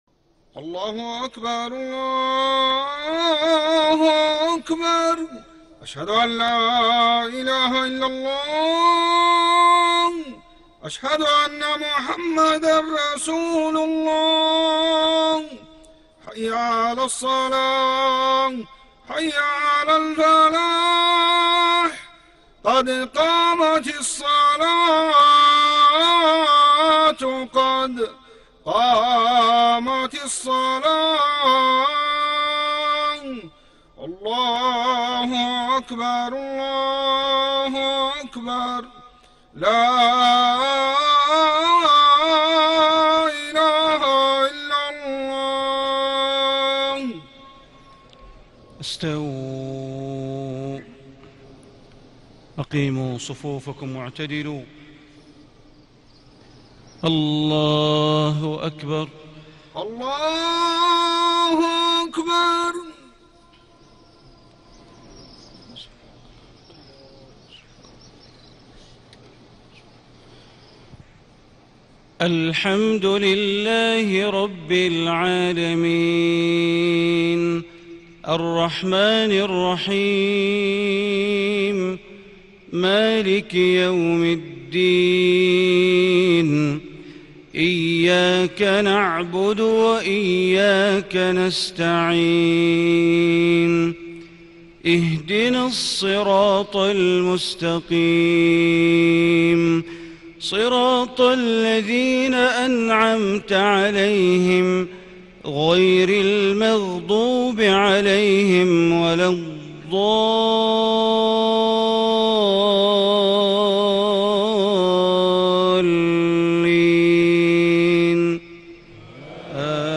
صلاة المغرب 1-8-1437هـ سورة البروج > 1437 🕋 > الفروض - تلاوات الحرمين